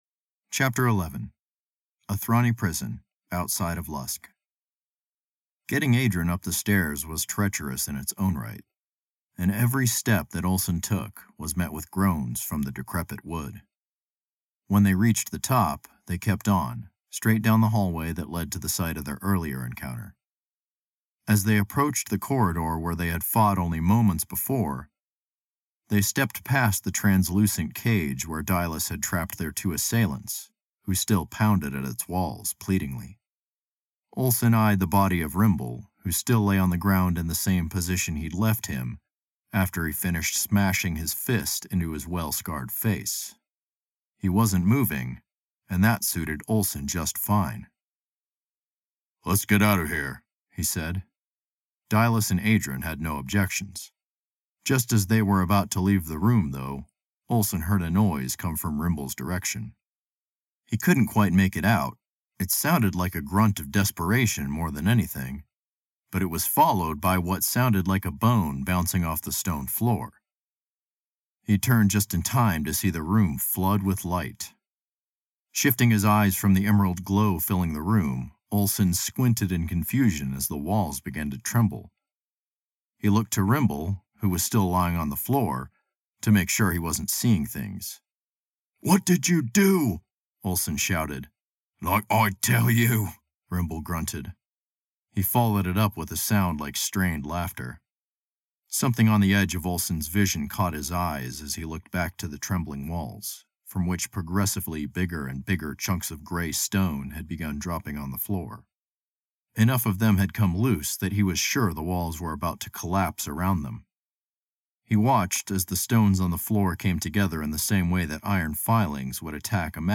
If you’re interested, I’ve also included a short (5 minute) cut from chapter 11 of the audiobook.